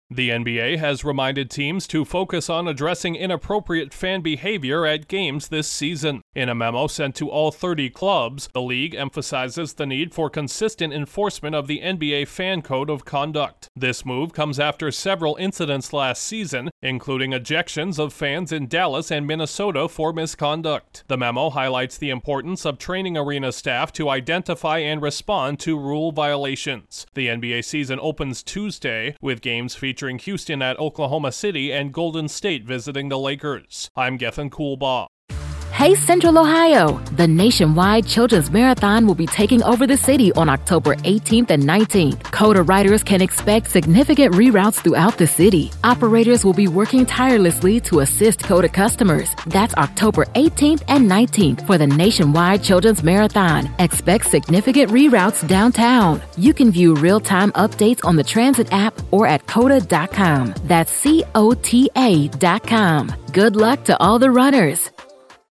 A major North American sports league is placing a greater emphasis on controlling fan behavior this season. Correspondent